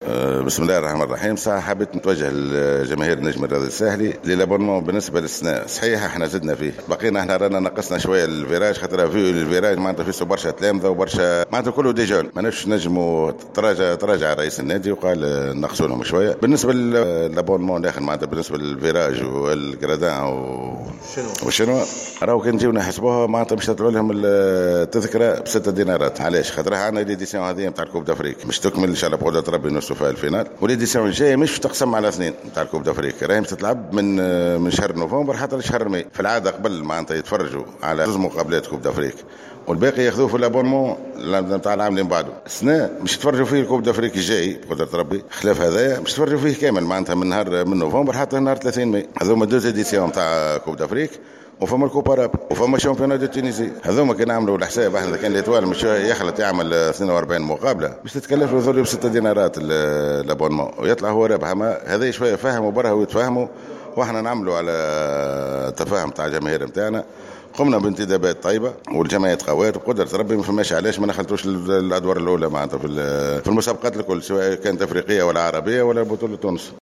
تصريح لجوهرة اف أم